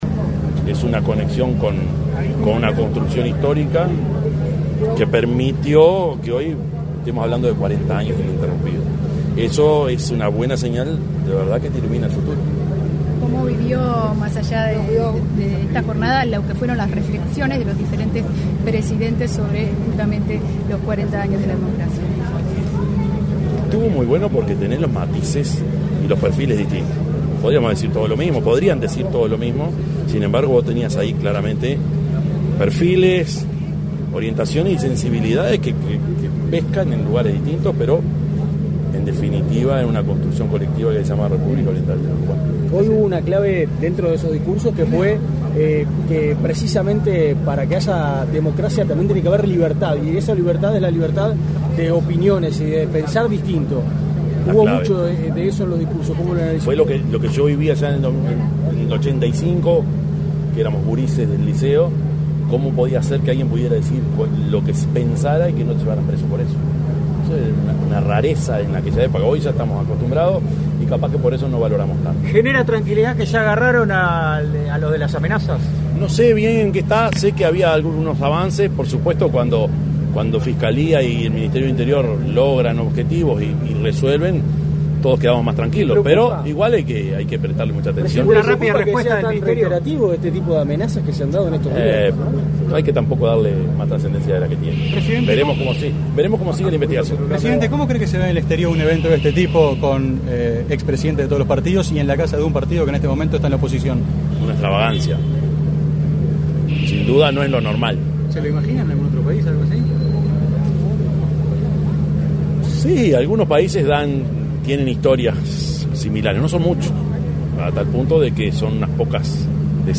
Declaraciones a la prensa del presidente de la República, Yamandú Orsi
Declaraciones a la prensa del presidente de la República, Yamandú Orsi 28/03/2025 Compartir Facebook X Copiar enlace WhatsApp LinkedIn El presidente de la República, profesor Yamandú Orsi, participó, este 27 de marzo, en el encuentro de presidentes con motivo de celebrar los 40 años de la democracia desde 1985, en la casa del Partido Colorado. Tras el evento, realizó declaraciones a la prensa.